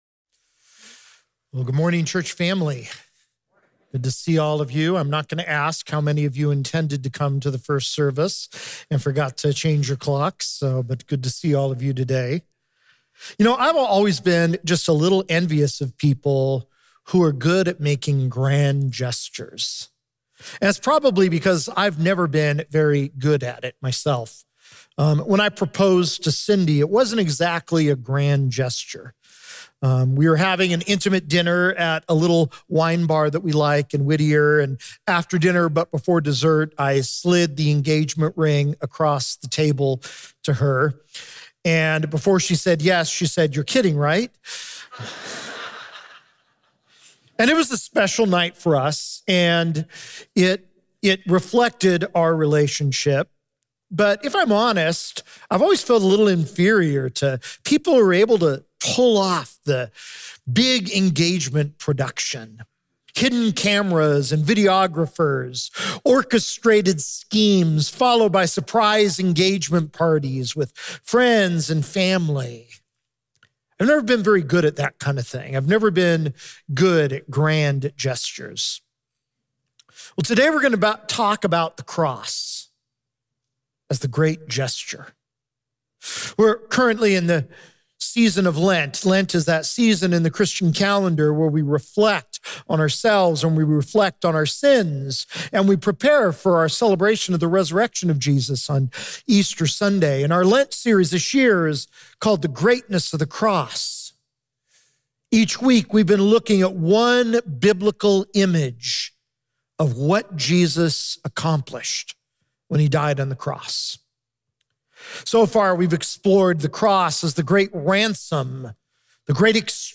March 10, 2024 – The Great Gesture (Message Only) – Glenkirk Church